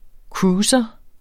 Udtale [ ˈkɹuːsʌ ]